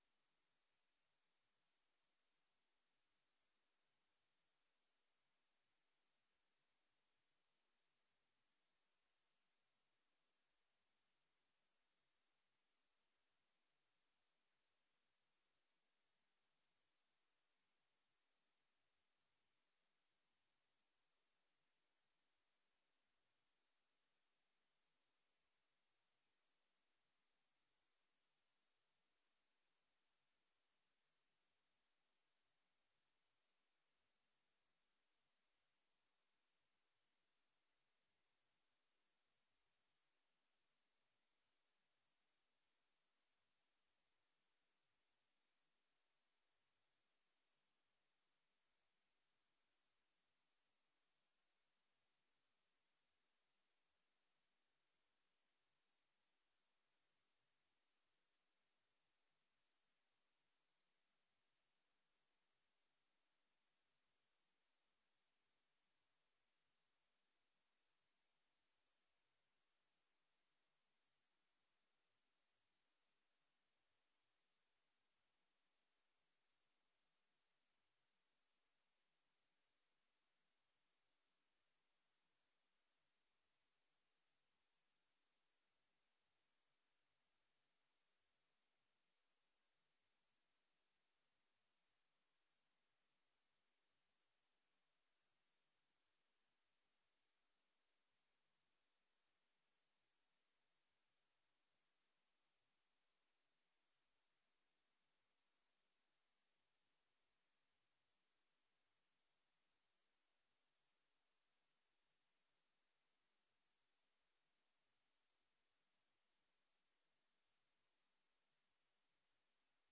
Download de volledige audio van deze vergadering
Locatie: Commissiekamer